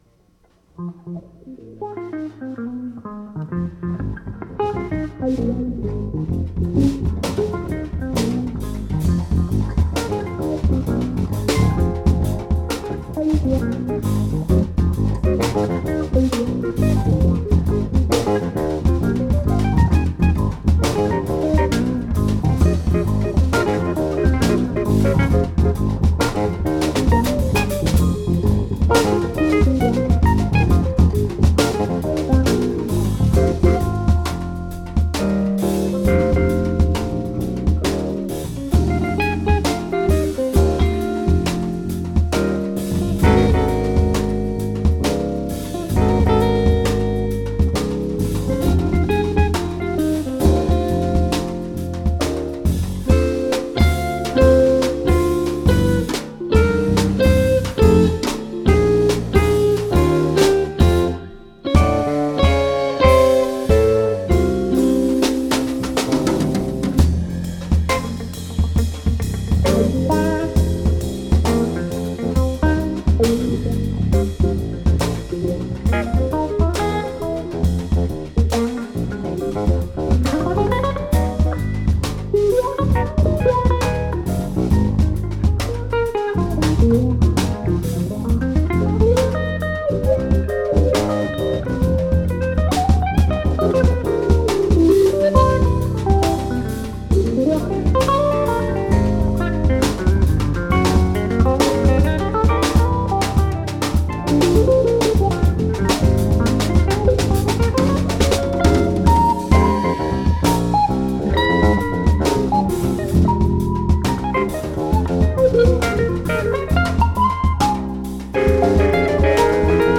live at the Red Poppy Art House in San Francisco
Guitar
Keyboards
Electric Bass
Drums